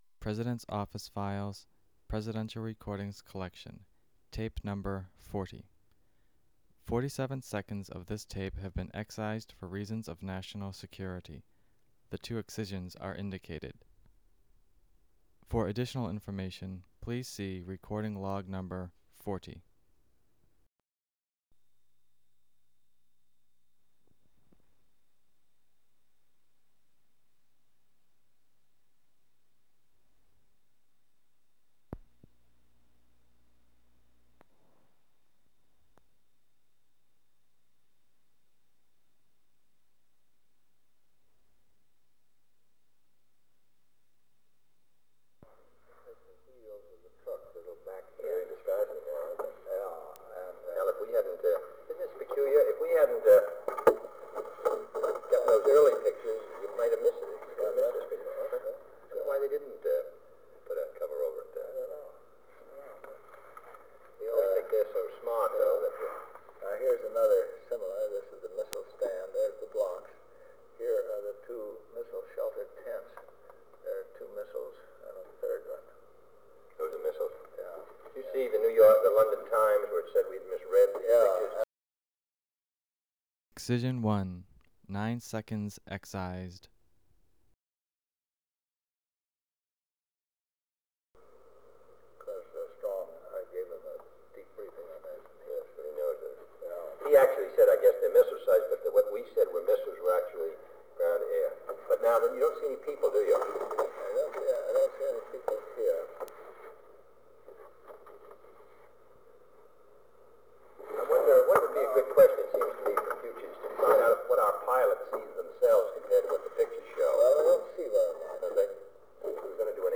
Meeting with Intelligence Officials
Secret White House Tapes | John F. Kennedy Presidency Meeting with Intelligence Officials Rewind 10 seconds Play/Pause Fast-forward 10 seconds 0:00 Download audio Previous Meetings: Tape 121/A57.